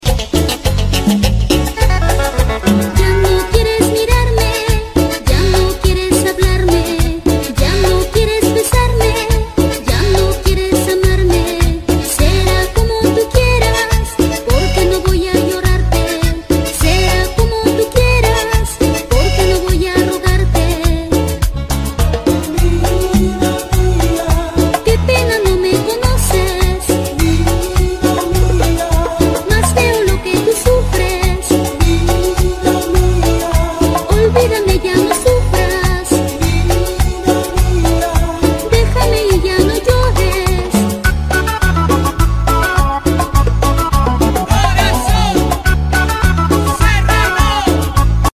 ella fue la mejor voz de corazón cerrano